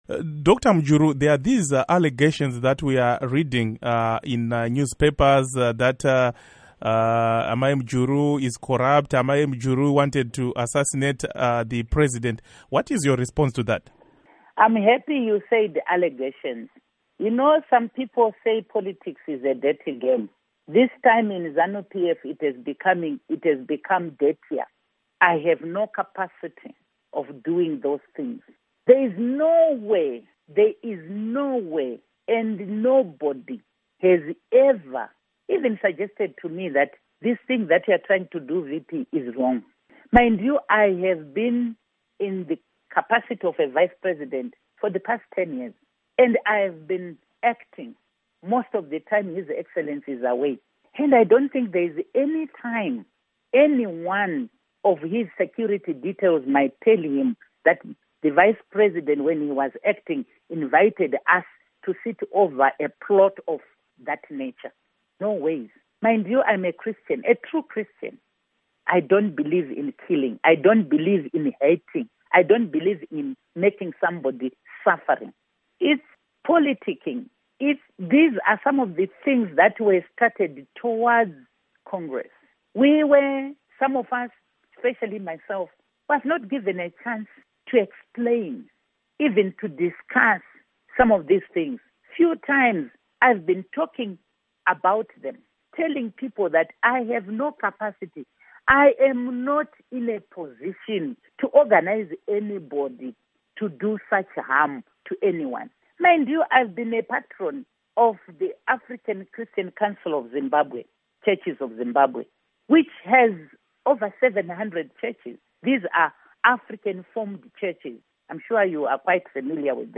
Interview With Joyce Mujuru